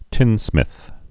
(tĭnsmĭth)